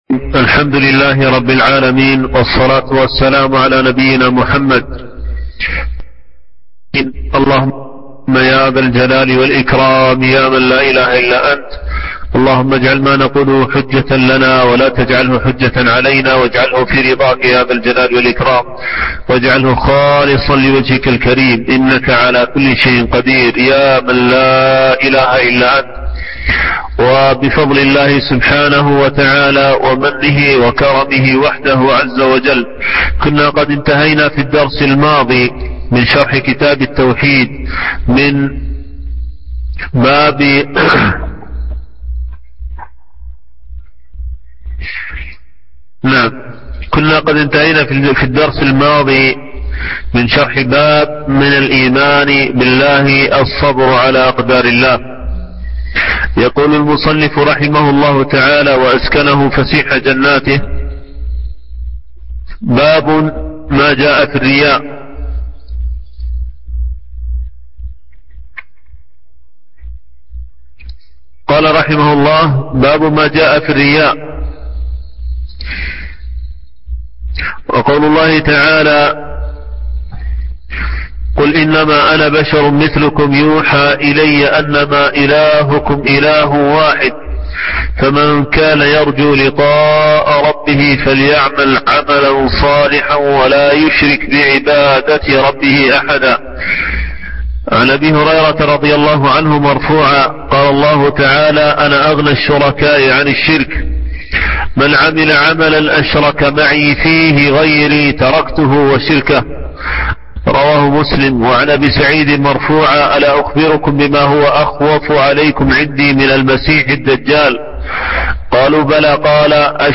شرح كتاب التوحيد - الدرس الثاني والثلاثون